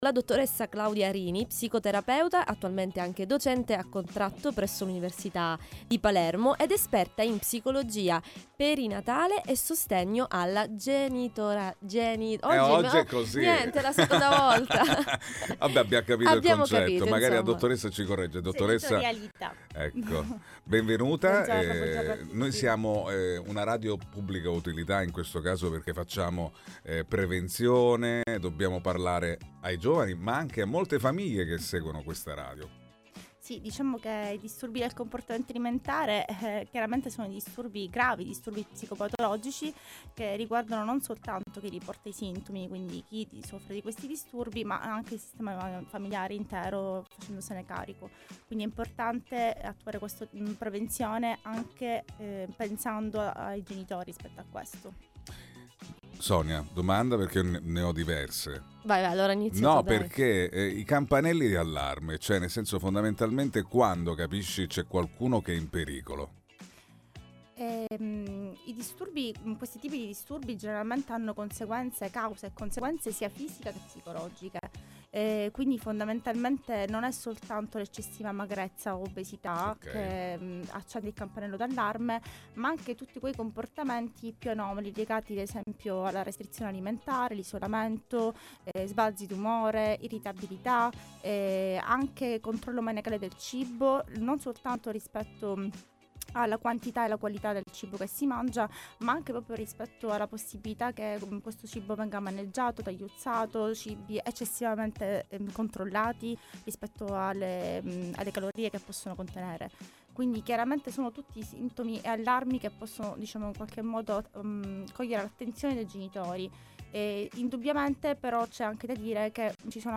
All Inclusive Interviste